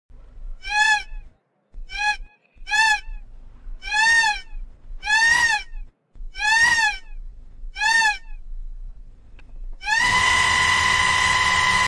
Chicken Scream Prout Botão de Som
Play and download the Chicken Scream Prout sound effect buttons instantly!